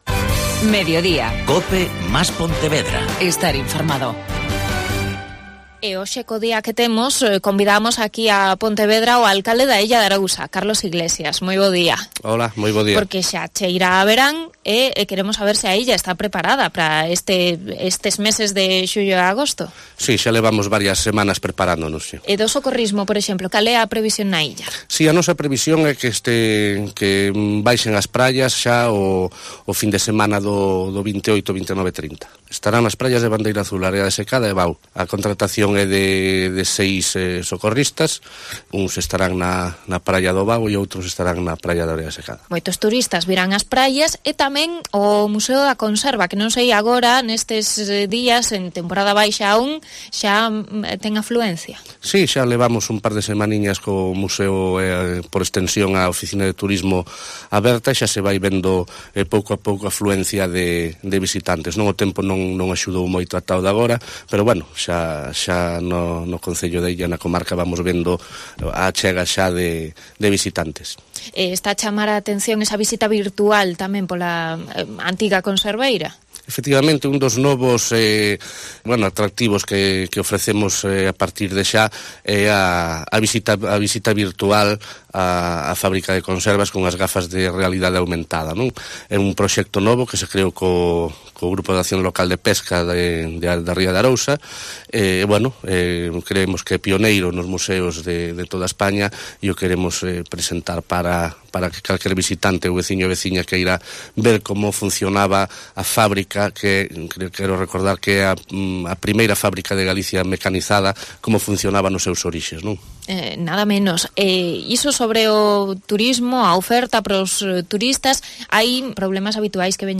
Entrevista a Carlos Iglesias, alcalde de A Illa